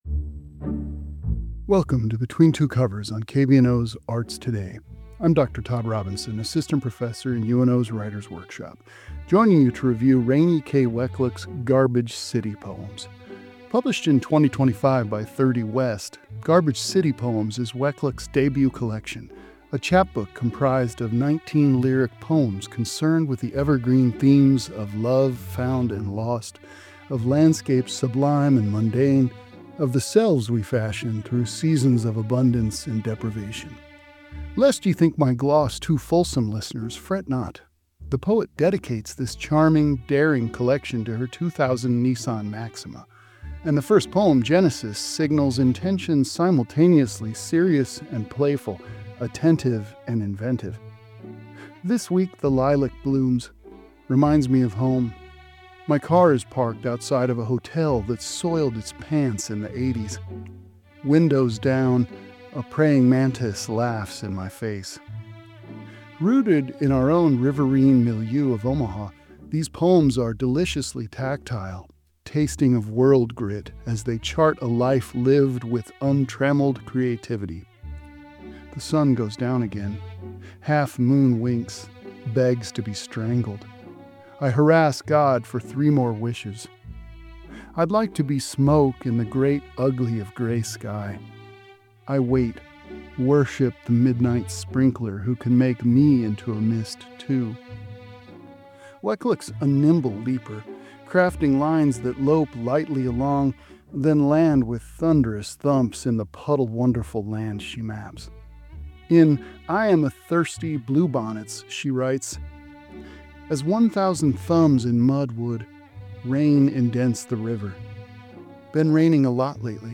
Presented as part of KVNO’s ongoing literary segment, Between Two Covers explores how writers use language to make meaning.